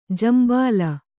♪ jambāla